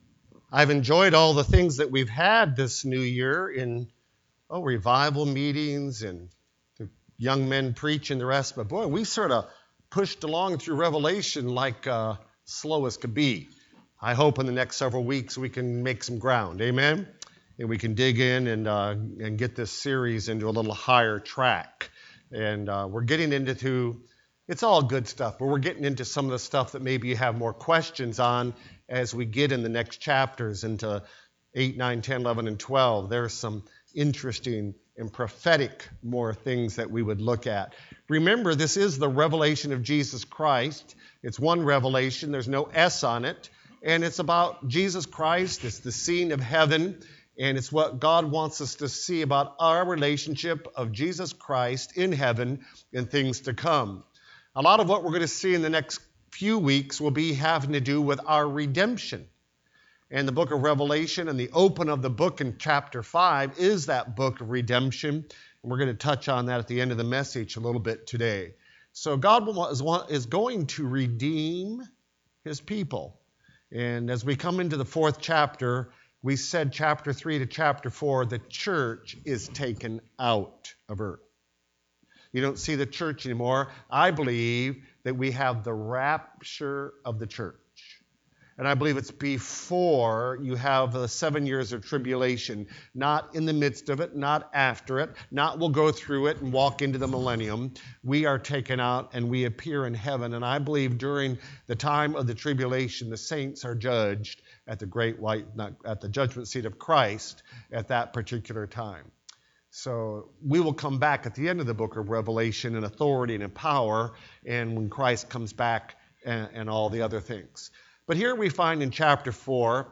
The Revelation Service Type: Sunday Evening Preacher